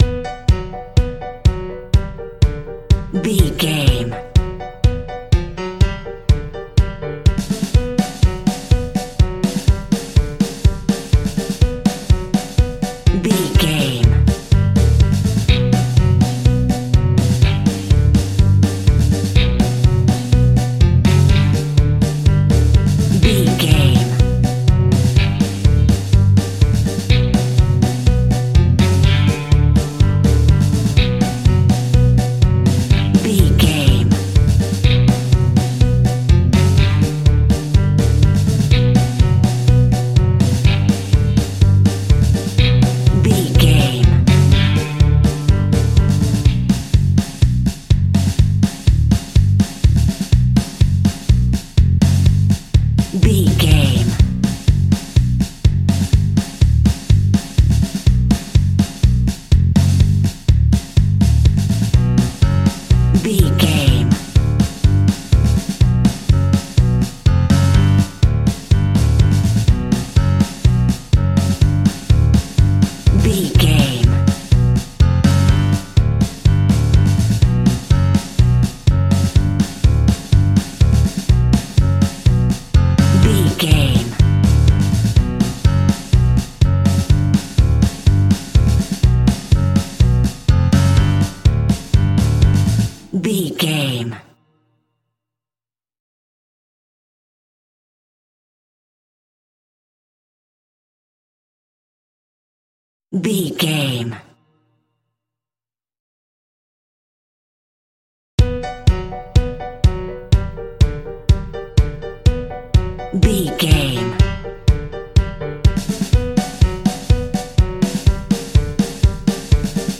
Diminished
scary
ominous
dark
haunting
eerie
piano
drums
bass guitar
electric guitar
creepy
horror music